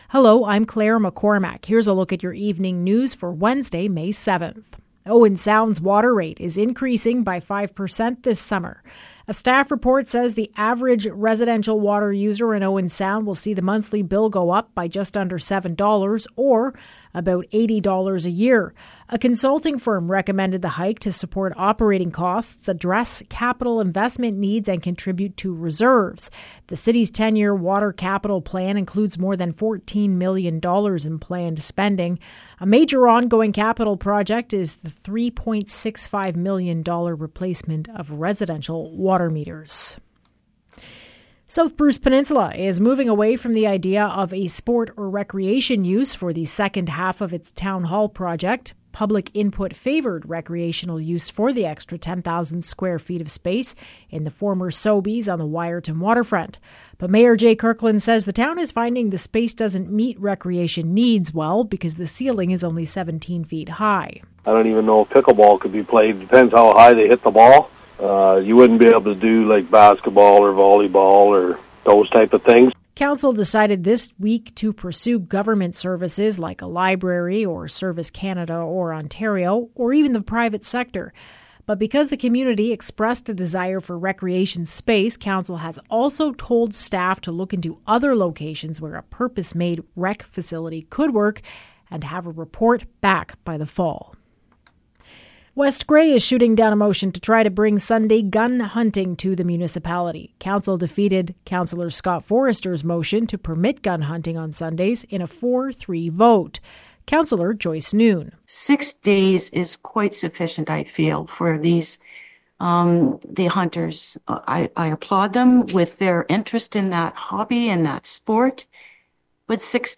Evening News – Wednesday, May 7